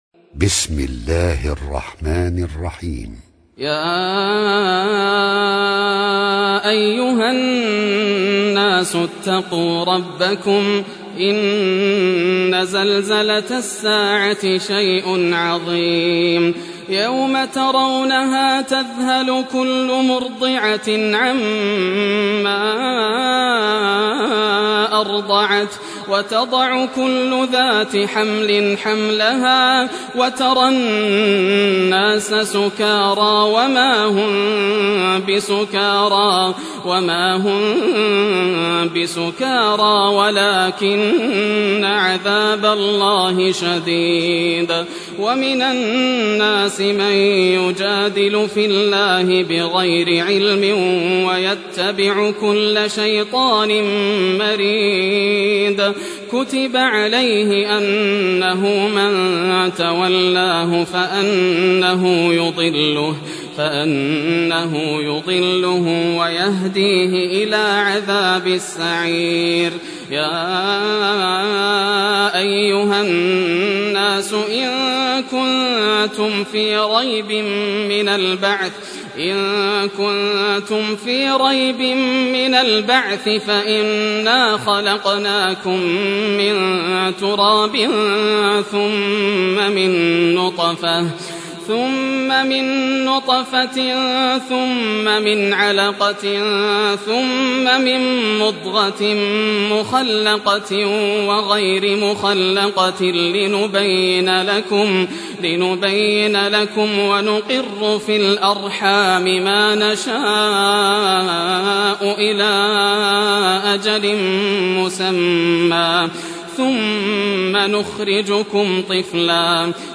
Surah Al-Hajj Recitation by Sheikh Yasser Dosari
Surah Al-Hajj, listen or play online mp3 tilawat / recitation in Arabic in the beautiful voice of Sheikh Yasser al Dosari.